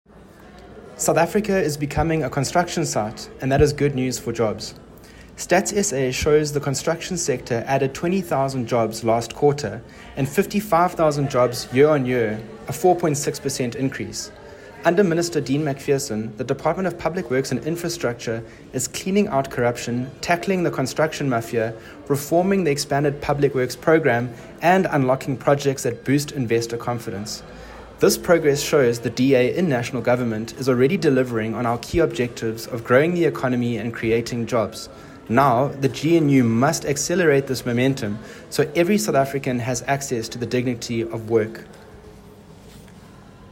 Issued by Edwin Macrae Bath MP – DA Deputy Spokesperson on Public Works and Infrastructure
soundbite by Edwin Macrae Bath MP.